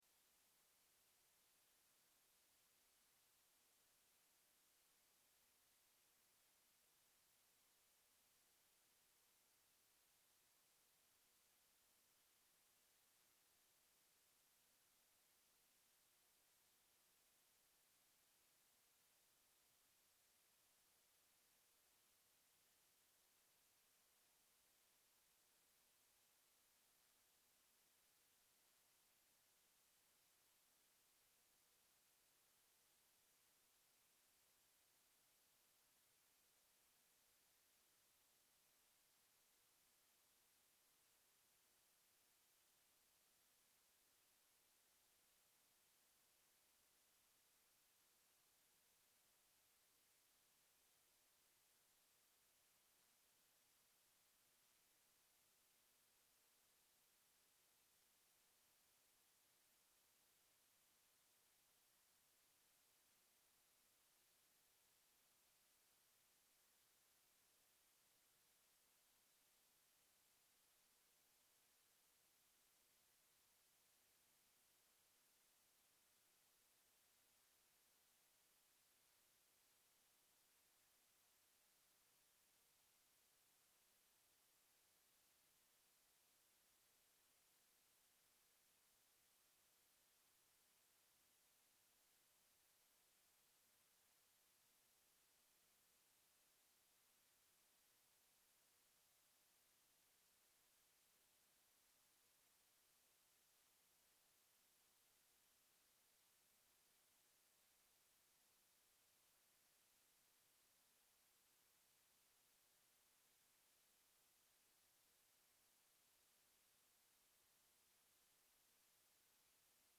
Sermon April 20, 2025, Easter Sunday
Sermon_April_20_2025_Easter_Sunday.mp3